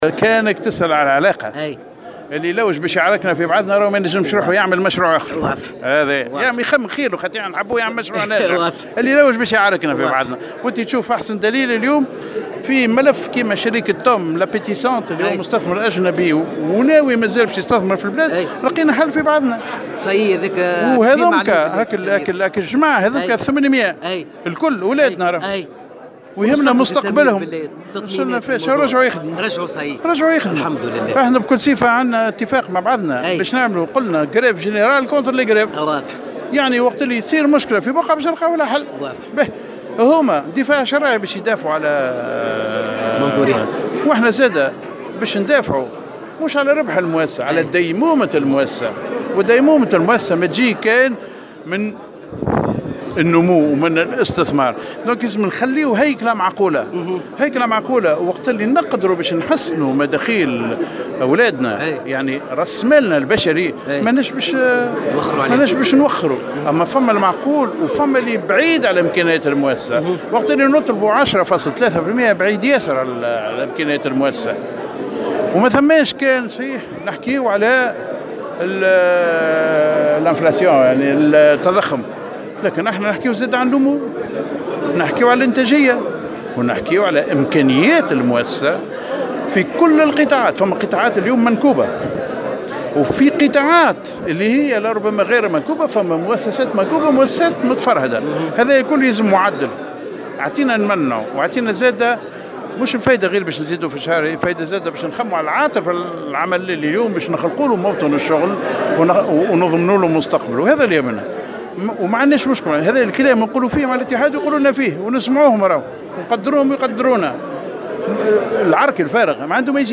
وأكد العلاقة الجيدة القائمة على التوافق بين المنظمتين والتي تحرص قياداتها على ايجاد حلول، وفق تصريحه لموفد "الجوهرة اف أم".